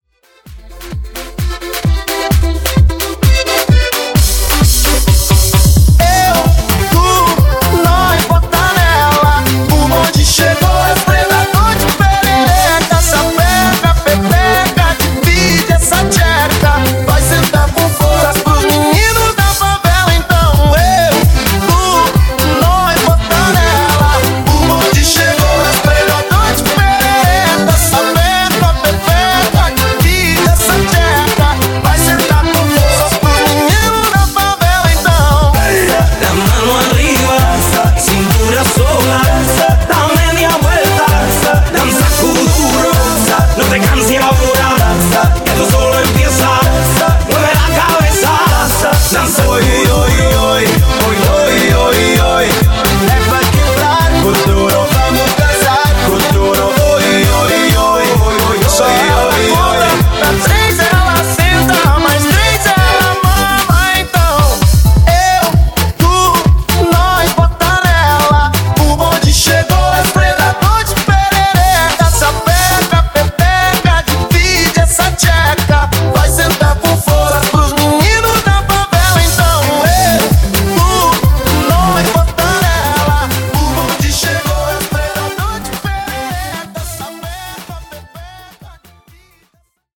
Party Mashup)Date Added